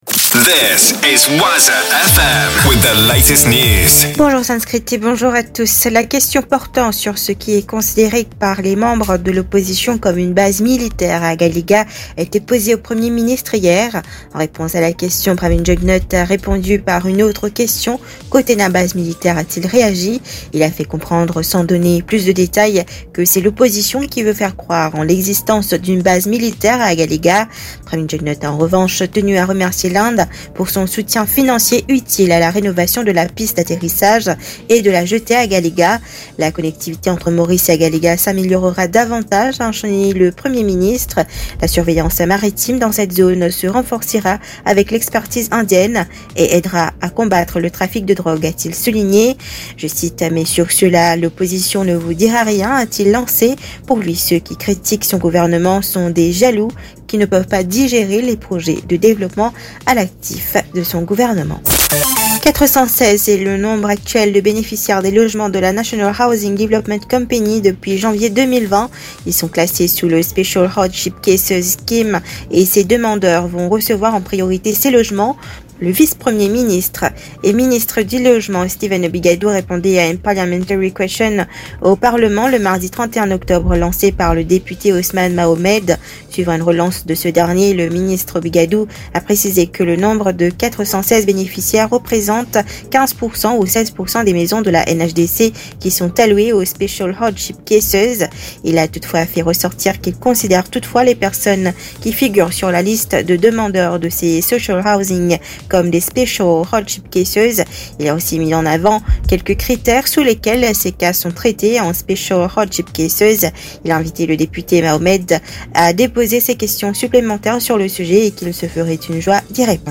news 7h - 3.11.23